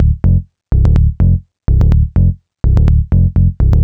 cch_basser_125_F#m.wav